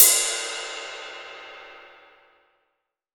Index of /90_sSampleCDs/AKAI S6000 CD-ROM - Volume 3/Ride_Cymbal1/20INCH_ZIL_RIDE